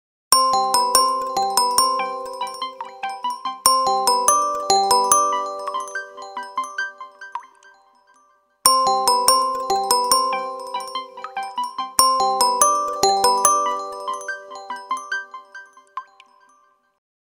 4. bell drop